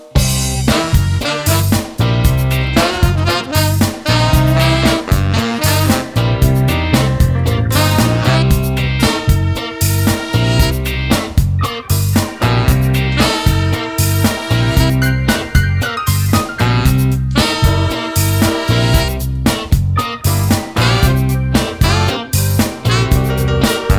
no Backing Vocals Soundtracks 3:54 Buy £1.50